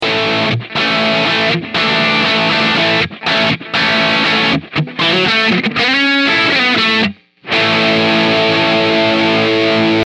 ドンシャリなセッティング
ベースが１０、ミドル０、トレブルが１０
かなり特徴があるので好きな人には堪らないセッティングです。
otodukuri-donsyari.mp3